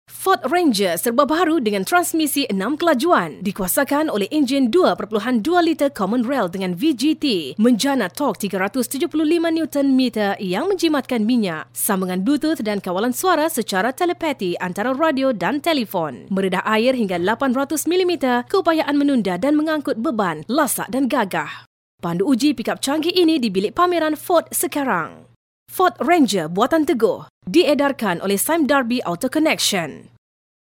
马来西亚语翻译团队成员主要由中国籍和马来籍的中马母语译员组成，可以提供证件类翻译（例如，驾照翻译、出生证翻译、房产证翻译，学位证翻译，毕业证翻译、成绩单翻译、无犯罪记录翻译、营业执照翻译、结婚证翻译、离婚证翻译、户口本翻译、奖状翻译等）、公证书翻译、病历翻译、马来语视频翻译（听译）、马来语语音文件翻译（听译）、技术文件翻译、工程文件翻译、合同翻译、审计报告翻译等；马来西亚语配音团队由马来西亚籍的马来语母语配音员组成，可以提供马来语专题配音、马来语广告配音、马来语教材配音、马来语电子读物配音、马来语产品资料配音、马来语宣传片配音、马来语彩铃配音等。
马来西亚语样音试听下载